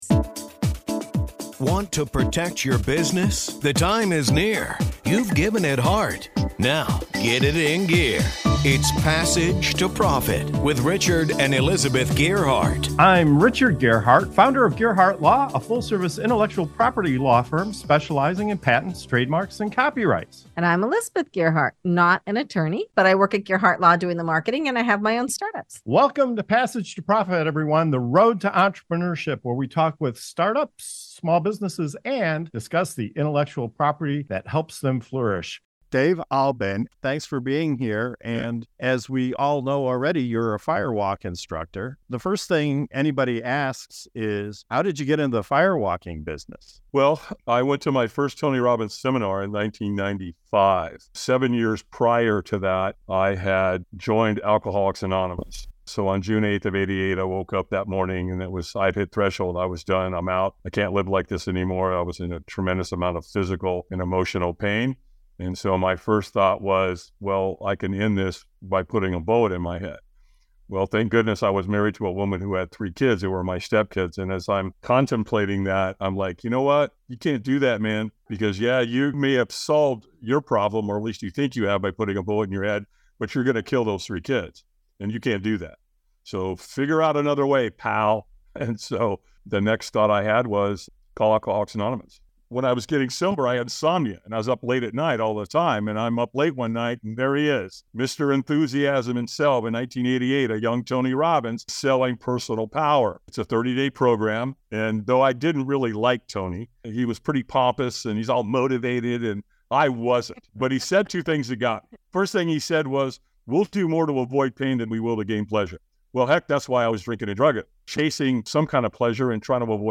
This is a segment from the full episode released on 10/8/23.